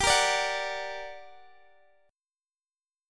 GmM7 chord